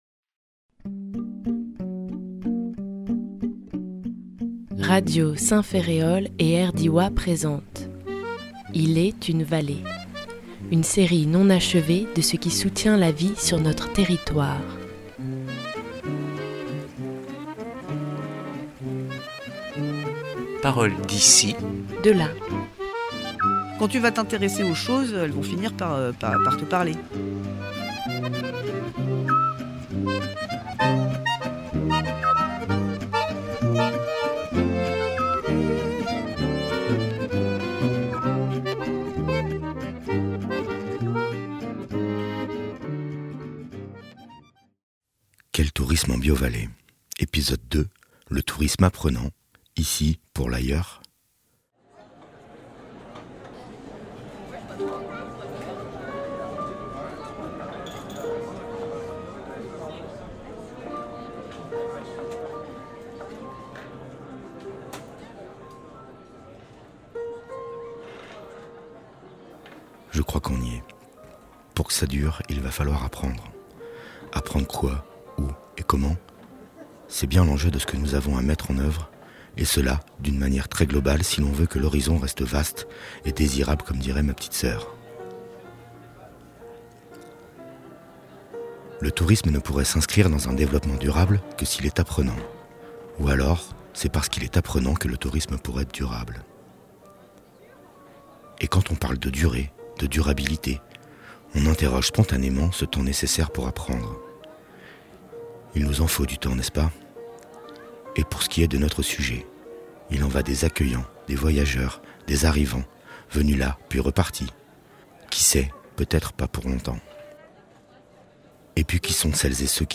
Orientés par l’ association Biovallée , nous sommes allés à la rencontre des professionnels du secteur qui œuvrent pour que ce pan de l’économie se développe tout en restant au service d’une société durable. Nous parlerons donc ici de tourisme durable.
Mais apprendre quoi, comment et dans quel but, c’est bien ce que nous vous proposons d’étudier avec nous au cours d’une série de quatre documentaires.